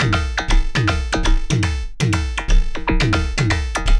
Robot Beat.wav